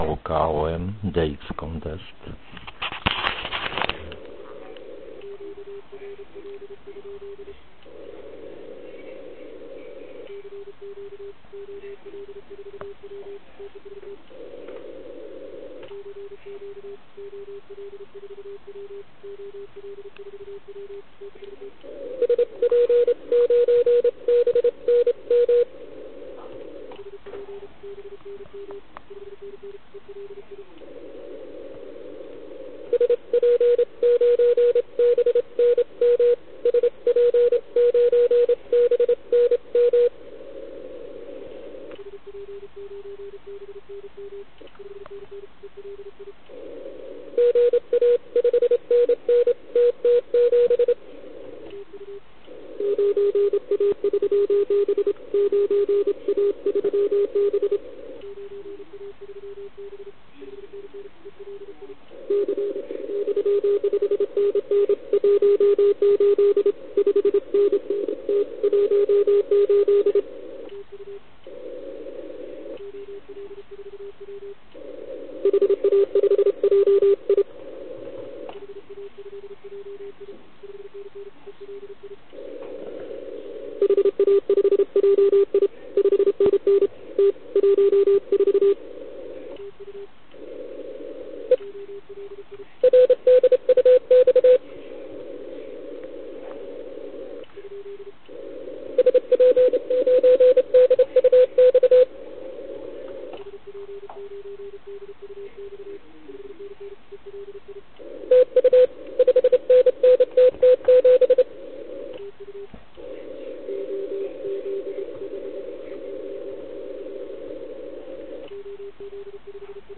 Připravil jsem pro naše žáky tedy 3 nahrávky z pásma 80m, kde jsem jel v kategorii LP (do 100W).
Zde si všimněte "soundu" TS480 přes CW filtr 270Hz. Vypnuté DSP, stažená citlivost, AVC Fast.